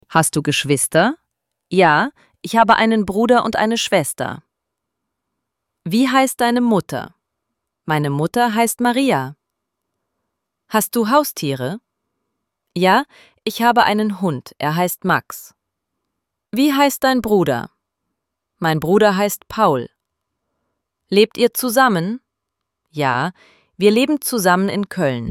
IZGOVOR – RJEŠENJA:
ElevenLabs_Text_to_Speech_audio-44.mp3